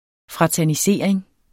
Udtale [ fʁɑtæɐ̯niˈseˀɐ̯eŋ ]